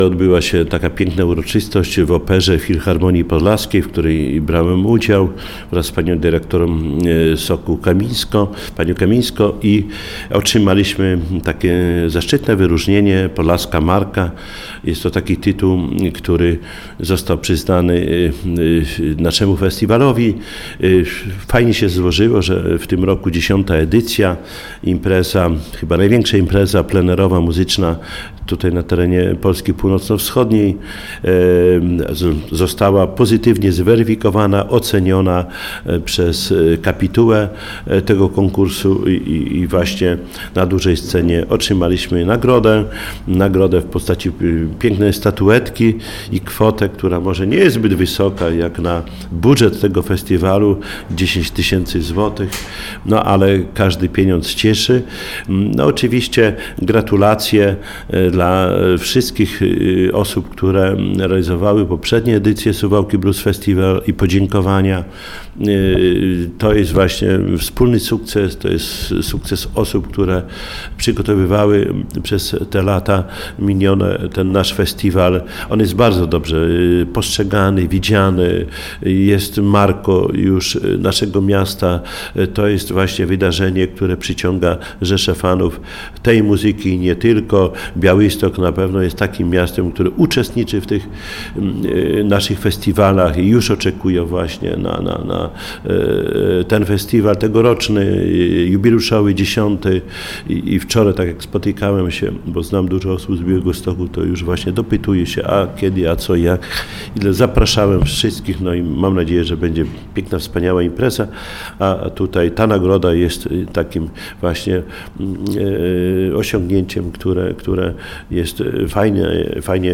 Szczegóły przedstawił na antenie Radia 5 Czesław Renkiewicz, Prezydent Suwałk.